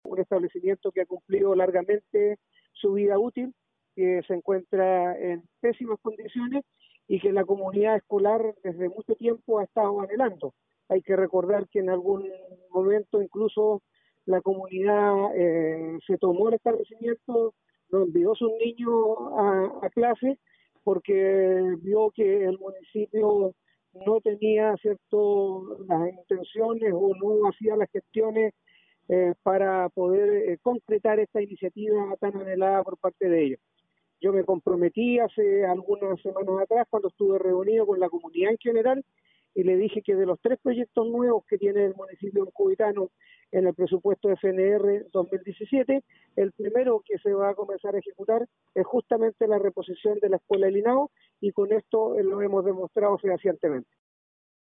A continuación, el edil de Ancud recordó los esfuerzos de la comunidad para hacer realidad este proyecto, incluso tomándose el establecimiento hace unos años atrás.